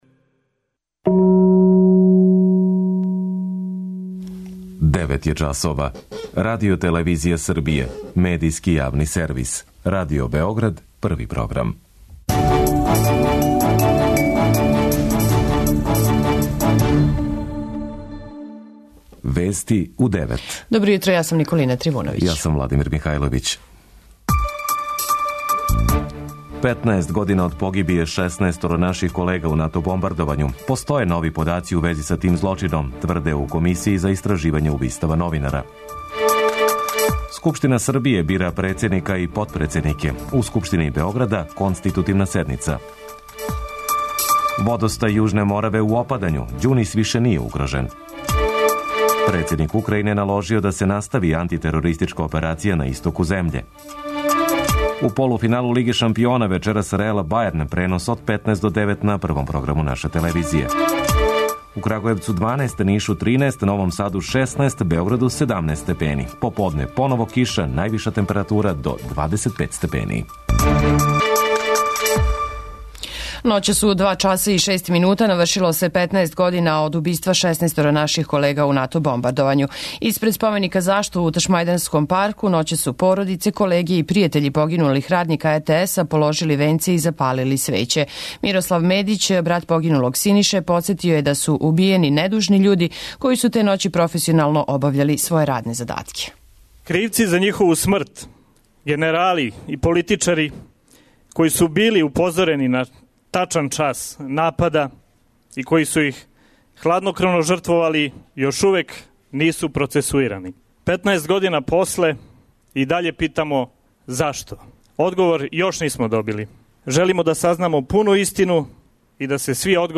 преузми : 10.11 MB Вести у 9 Autor: разни аутори Преглед најважнијиx информација из земље из света.